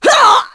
Nicky-Vox_Damage_kr_02.wav